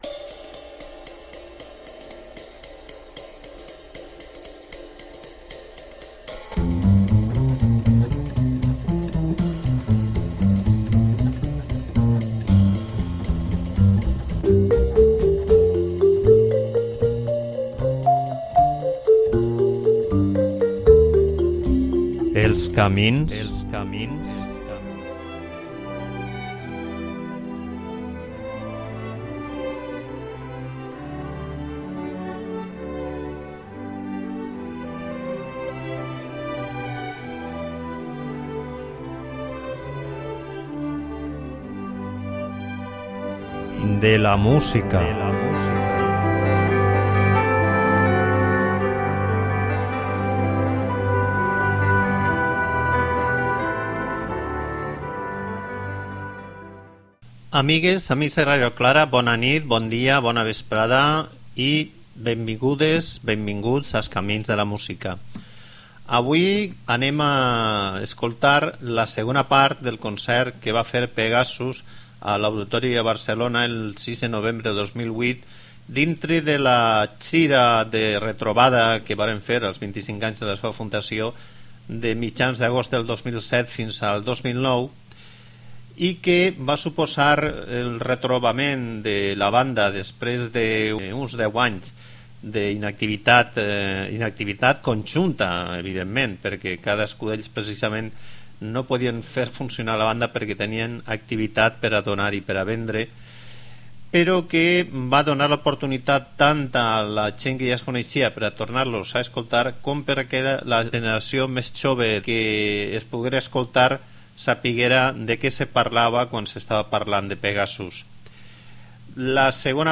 Aquest concert de l'any 2008 va ser una autèntica delícia.